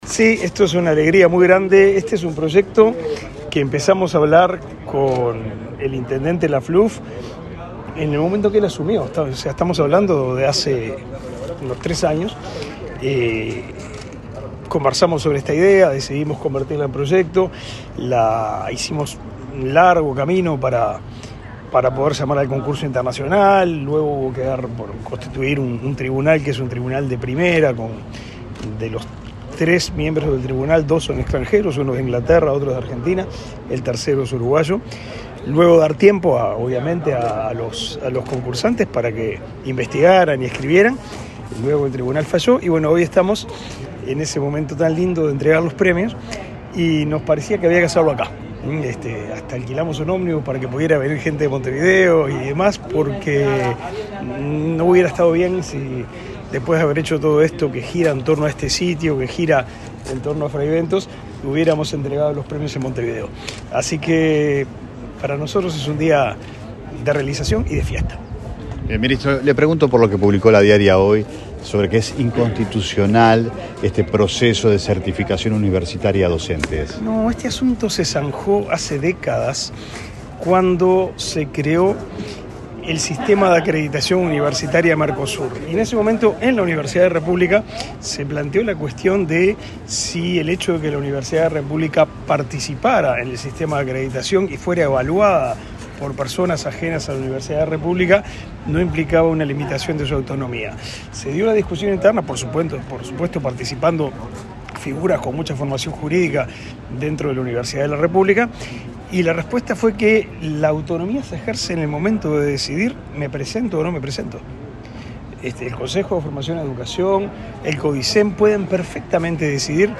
Declaraciones del ministro de Educación y Cultura, Pablo da Silveira
El ministro de Educación y Cultura, Pablo da Silveira, dialogó con la prensa en Río Negro, antes de participar en el acto de entrega de premios del